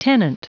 Prononciation du mot : tenant